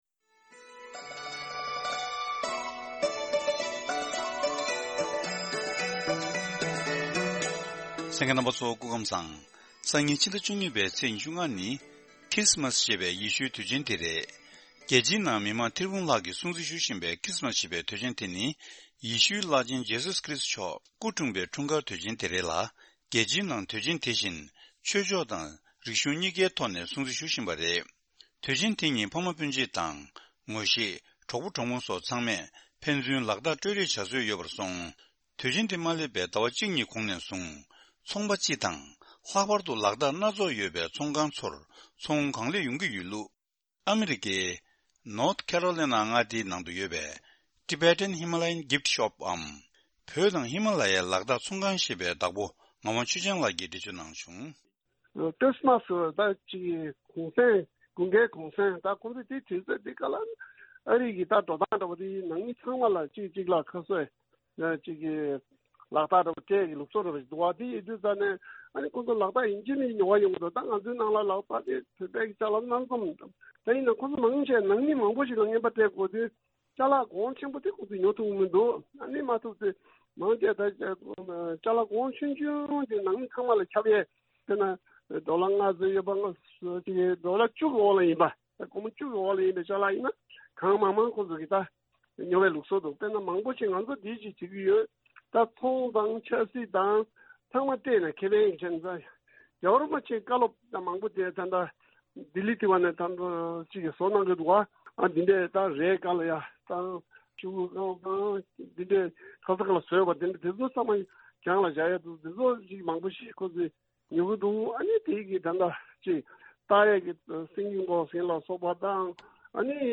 གནས་འདྲི་ཞུས་ནས་ཕྱོགས་སྒྲིགས་ཞུས་ པ་ཞིག་གསན་རོགས་གནང་།